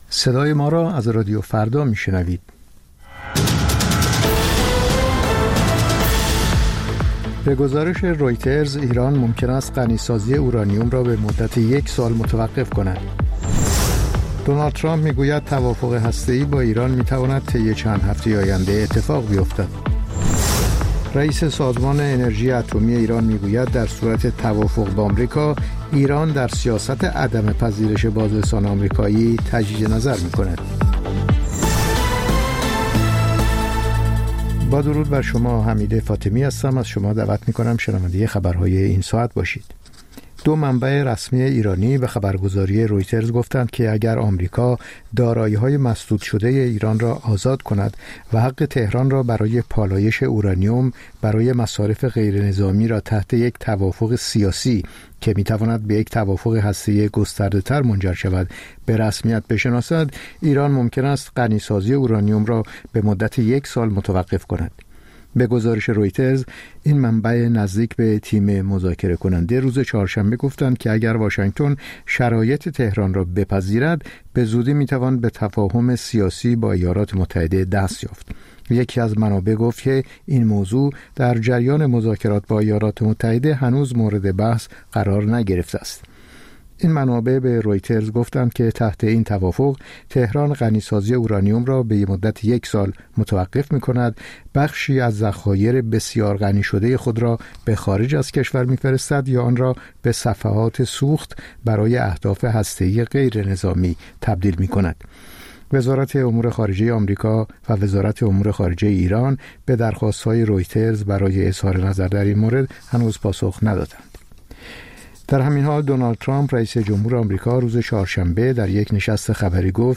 سرخط خبرها ۹:۰۰